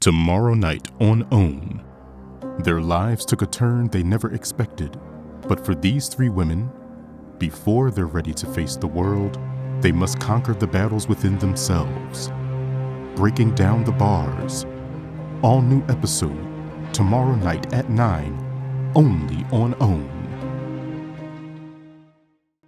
TV Promo Samples
Serious, Gloomy
TV-Promo-Demo_Breaking-Down-The-Bars.mp3